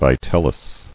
[vi·tel·lus]